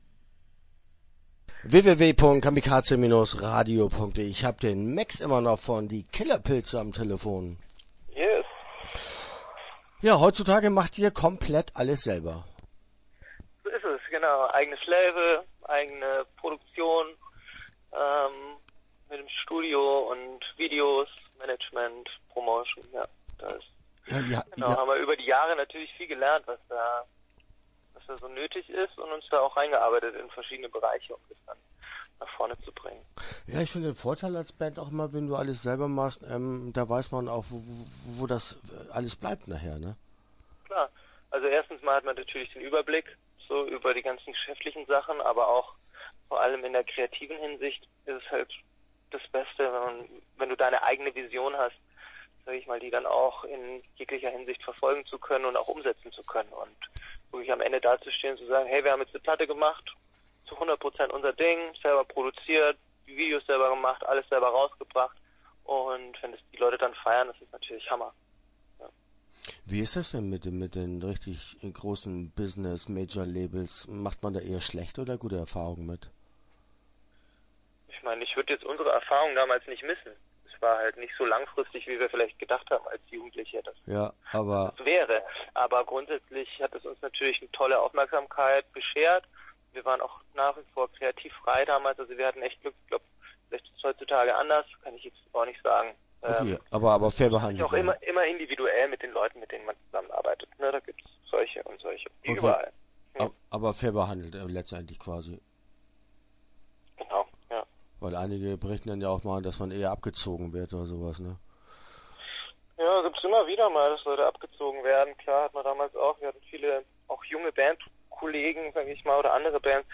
Die Killerpilze - Interview Teil 1 (8:26)